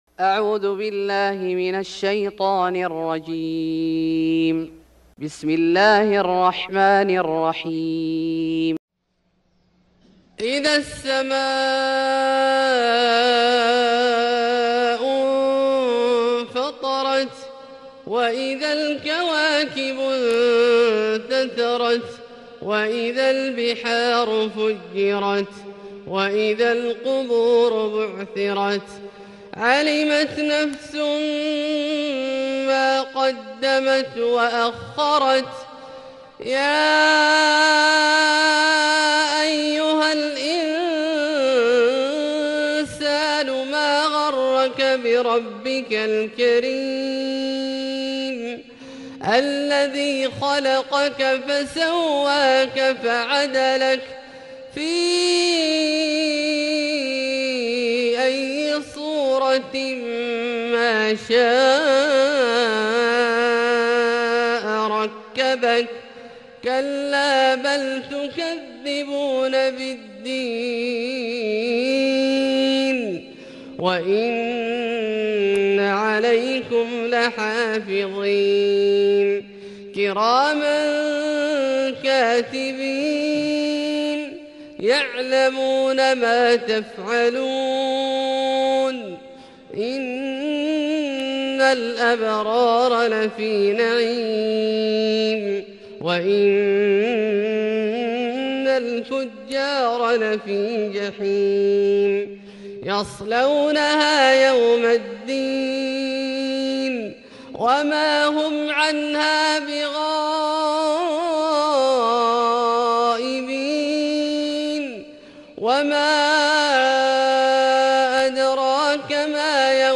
سورة الإنفطار Surat Al-Infitar > مصحف الشيخ عبدالله الجهني من الحرم المكي > المصحف - تلاوات الحرمين